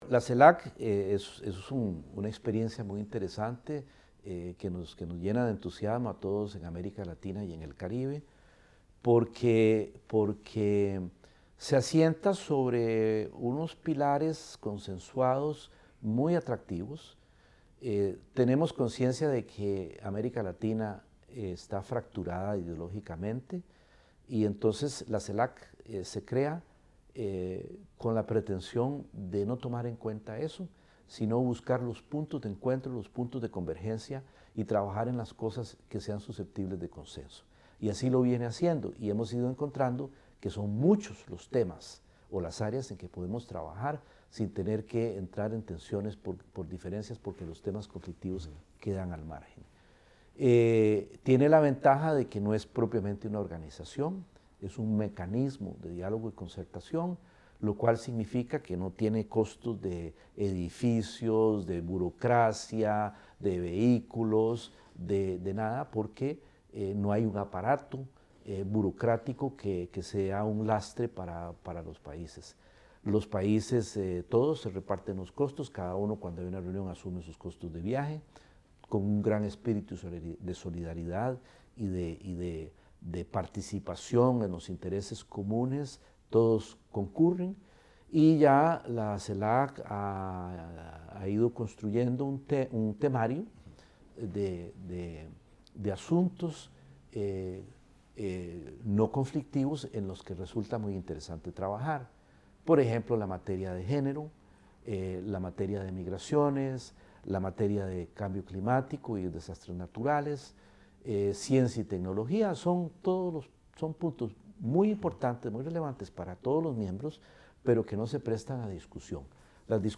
El ministro de Relaciones Exteriores de Costa Rica, Enrique Castillo, conversó con la Voz de América sobre sus relaciones económicas con EE.UU. y China, entre otros temas.